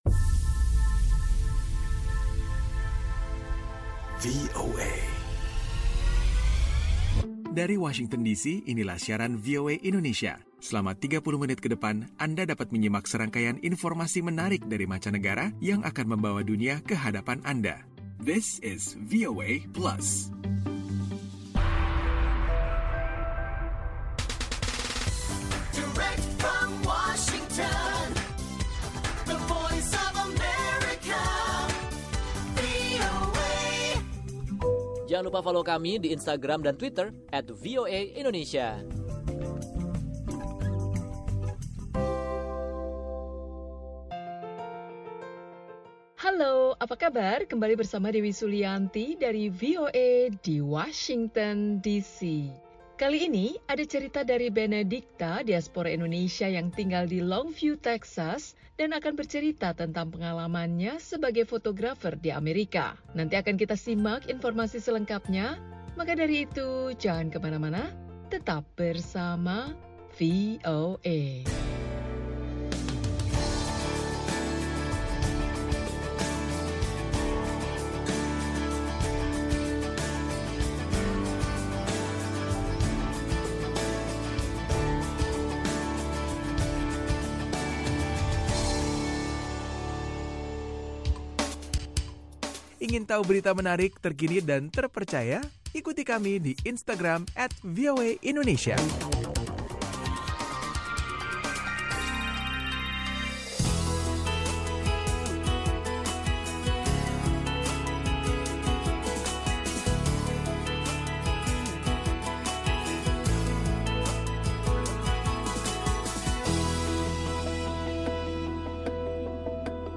VOA Plus kali ini akan mengajak anda menyimak obrolan bersama seorang diaspora Indonesia di negara bagian Texas seputar pekerjaannya sebagai tenaga kesehatan di rumah sakit, serta hobinya sebagai fotografer.